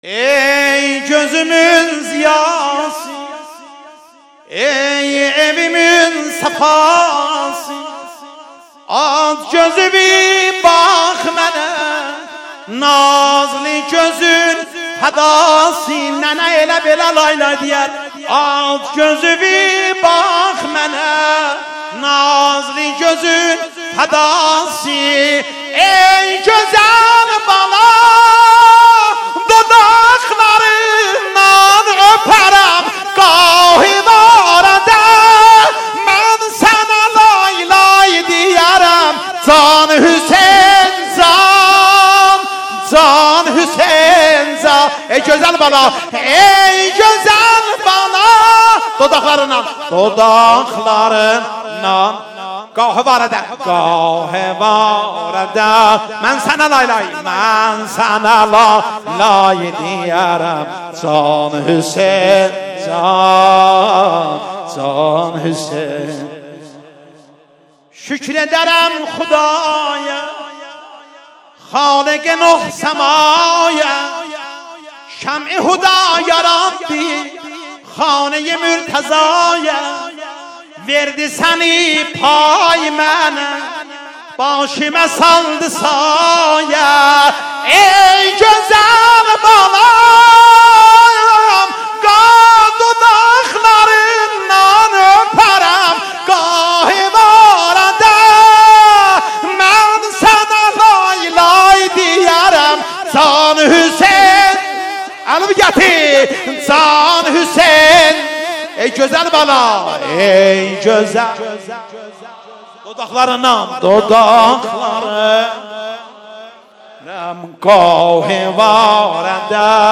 شب پنجم شعبان 1397 حسینیه اعظم زنجان